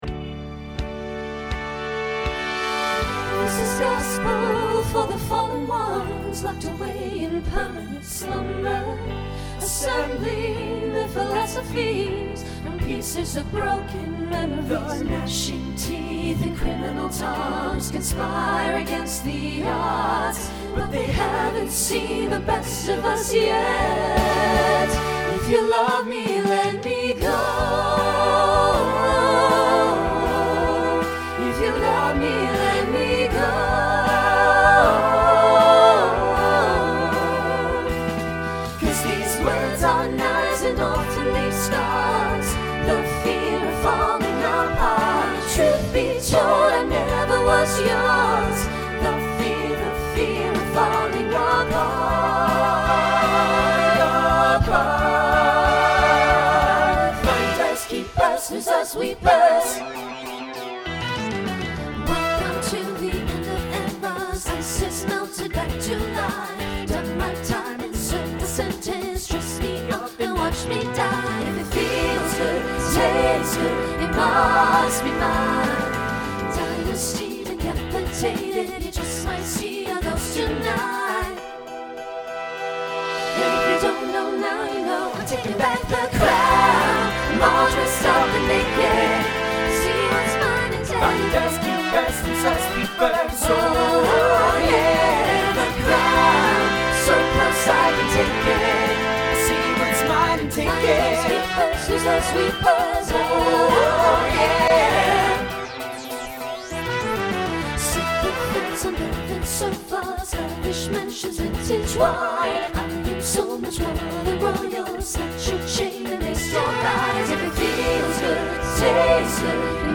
Voicing SATB
Genre Rock